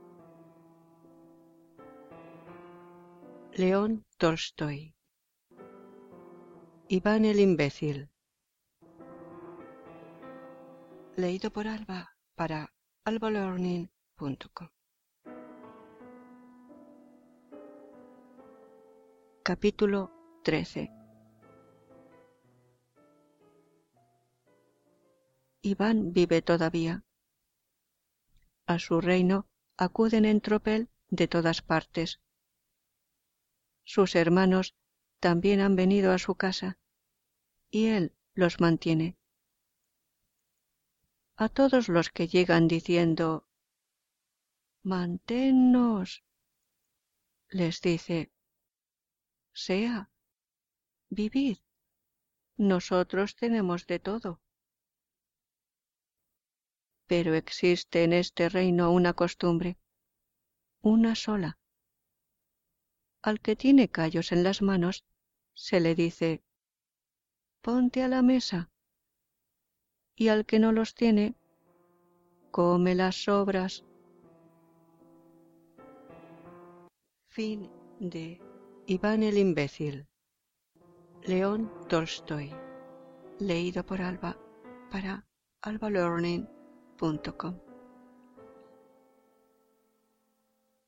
Música: Chopin - Op.34 no.2, Waltz in A minor